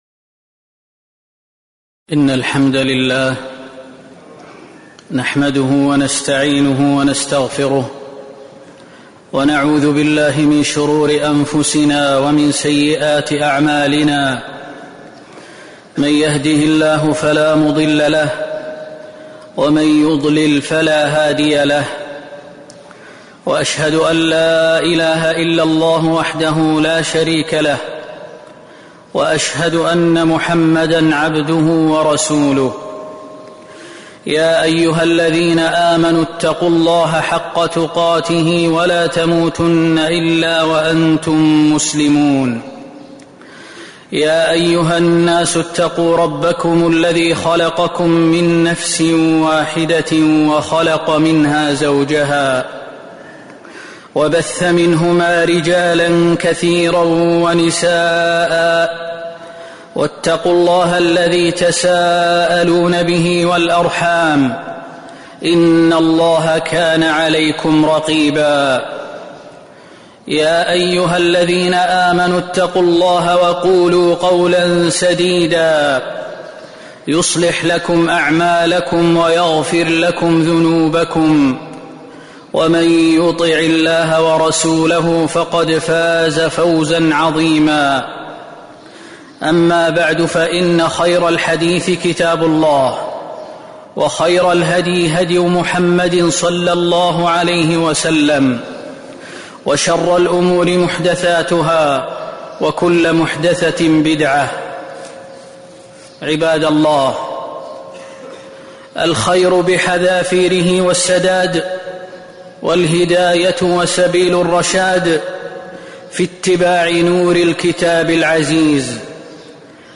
خطبة خشية الله تعالى وفيها: خشية الله هي خصيصة بأهل الإيمان، ومدح الله لأهل الخشية، ونوع الخشية المستنبطة من القرآن، وأسباب تحصيل الخشية
تاريخ النشر ٢٩ شوال ١٤٤٤ المكان: المسجد النبوي الشيخ: فضيلة الشيخ خالد المهنا فضيلة الشيخ خالد المهنا خشية الله